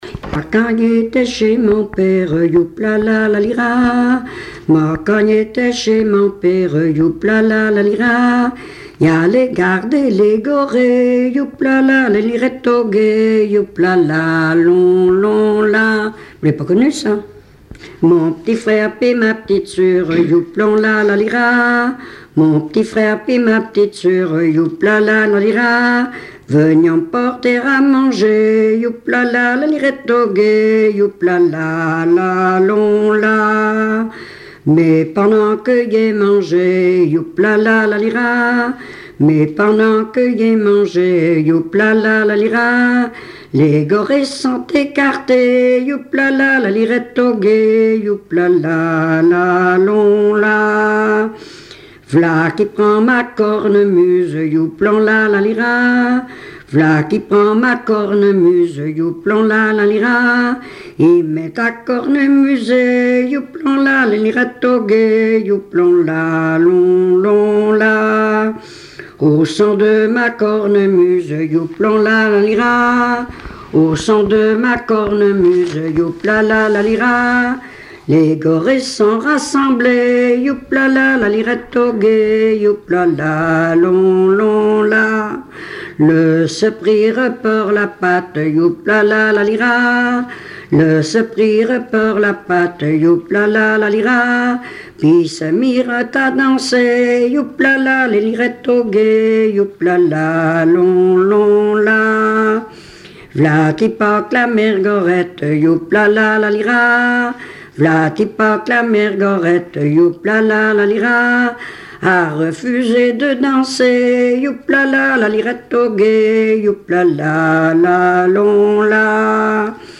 Saint-Martin-des-Noyers
Genre laisse
Chansons traditionnelles et populaires
Pièce musicale inédite